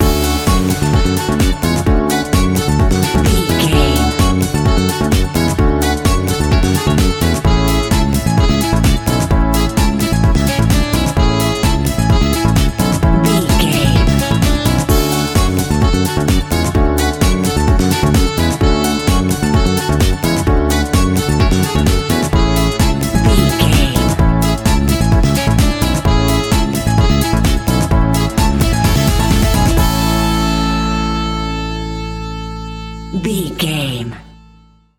Ionian/Major
groovy
uplifting
bouncy
cheerful/happy
electric guitar
bass guitar
drums
synthesiser
saxophone
deep house
nu disco
upbeat
funky guitar
wah clavinet